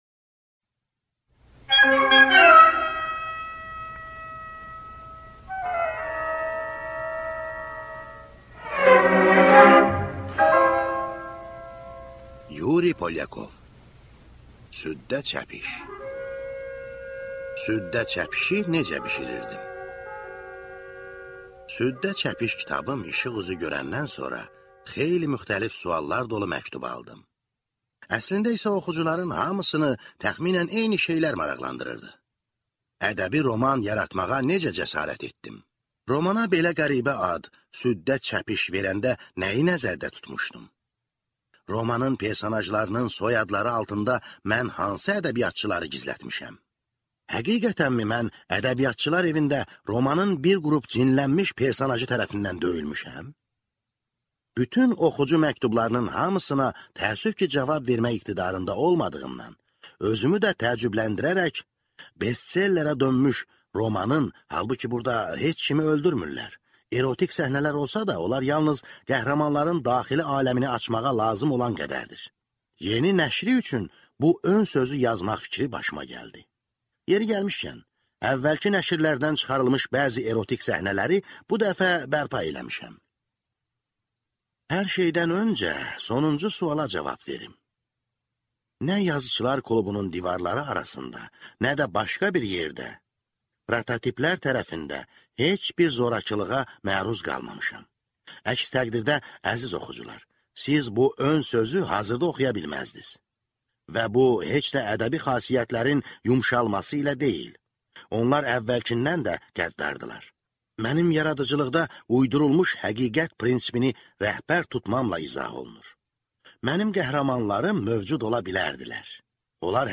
Аудиокнига Süddə çəpiş | Библиотека аудиокниг